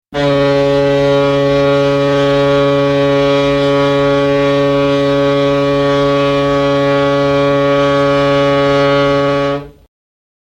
機械
長い警笛（164KB）